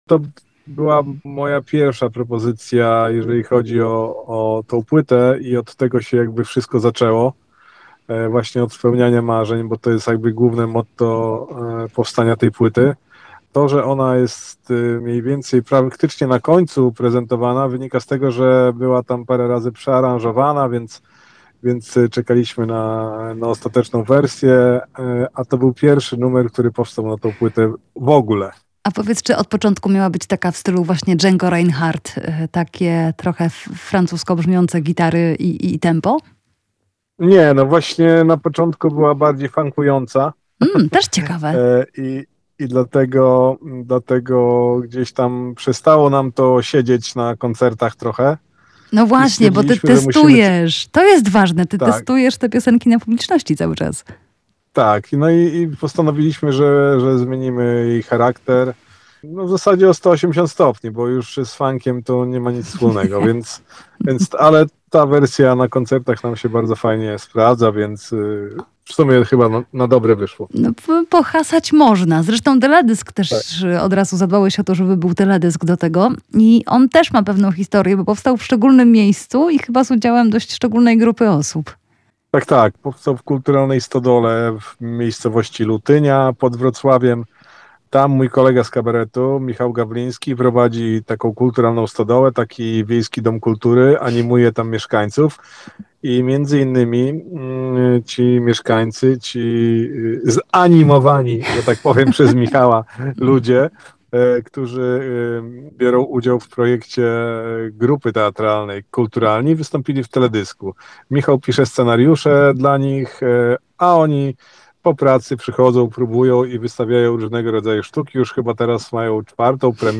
Radek Bielecki spełnia muzyczne marzenia [POSŁUCHAJ ROZMOWY]